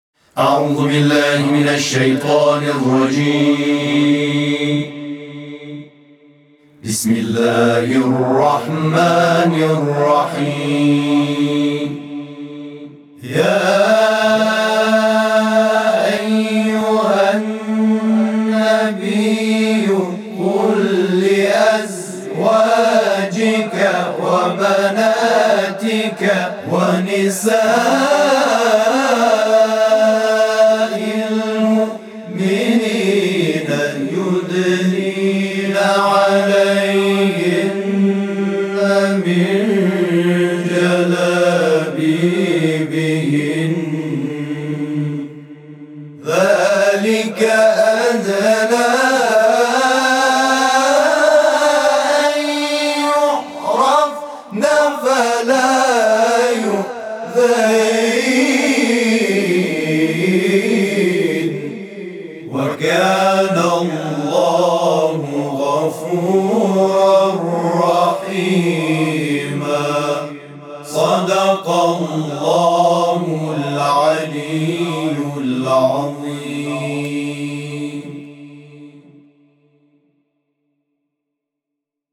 صوت همخوانی آیه 59 سوره احزاب از سوی گروه تواشیح «محمد رسول‌الله(ص)»